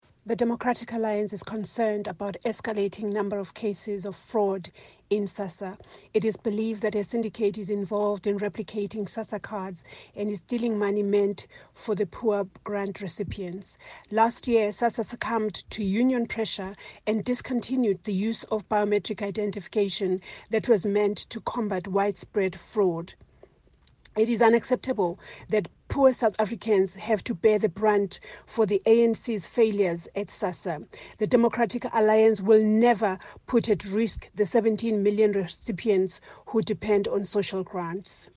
soundbite from Bridget Masango, the DA shadow Minister of Social Development.